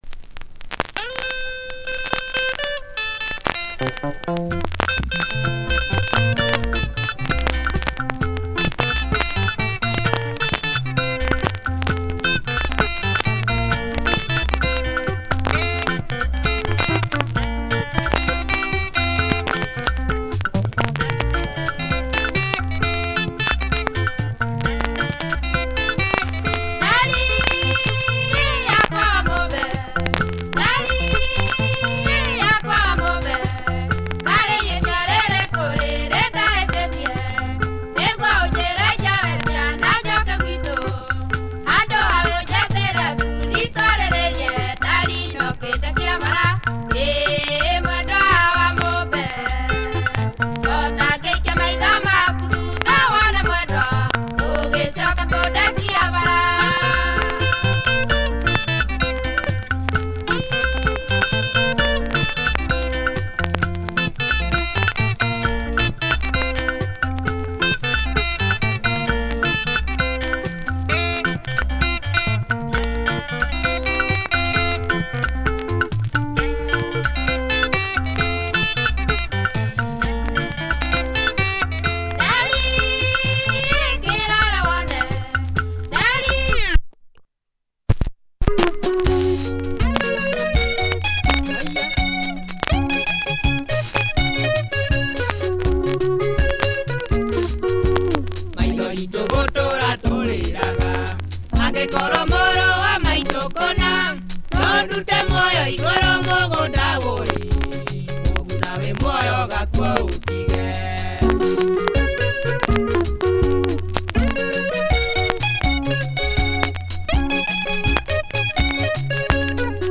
Typical Kikuyu benga doublesider, one with female vocals.
Disc has a few marks that will give some ticks.